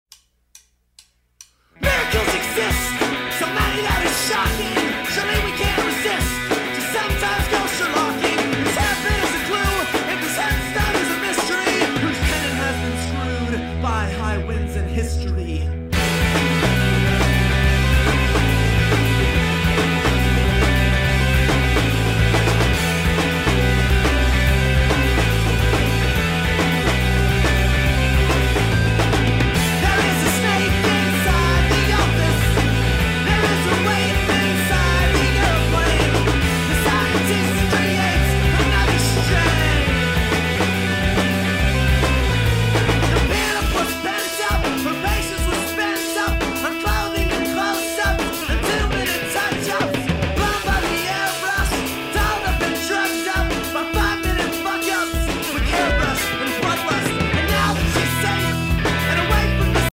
indie/art-punk band